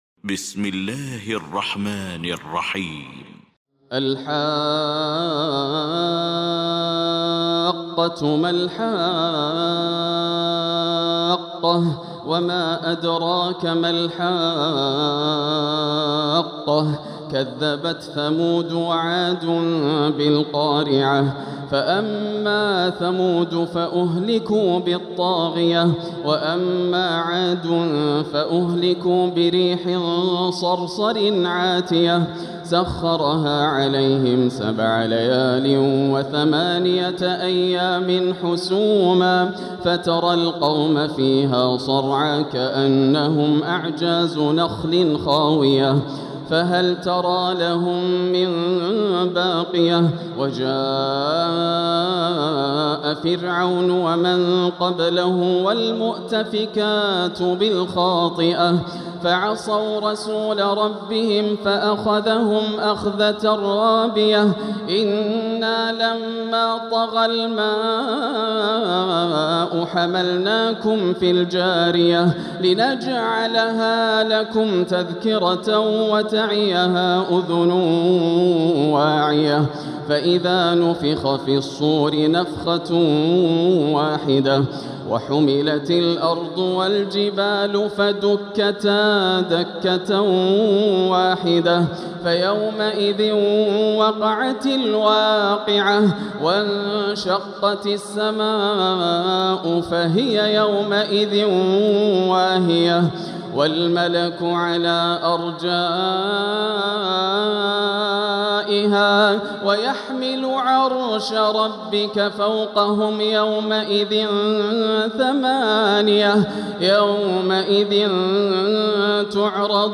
المكان: المسجد الحرام الشيخ: فضيلة الشيخ ياسر الدوسري فضيلة الشيخ ياسر الدوسري الحاقة